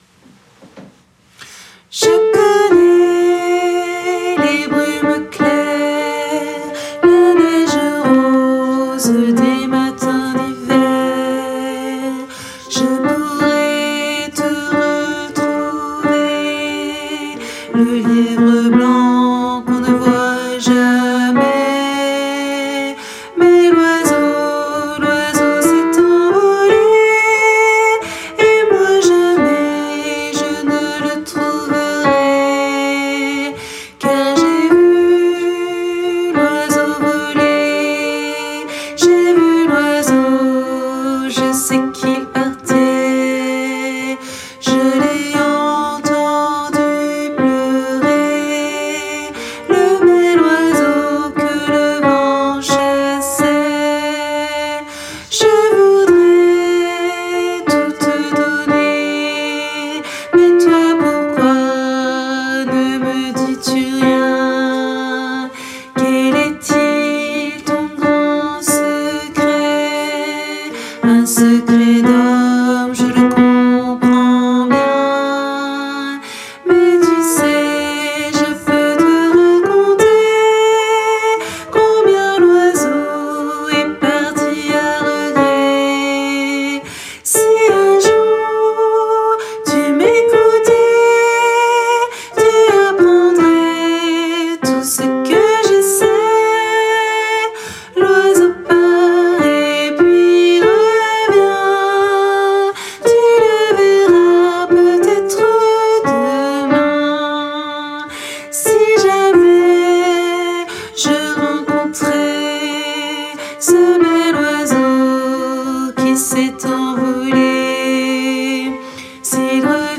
Soprano Et Autres Voix En Arriere Plan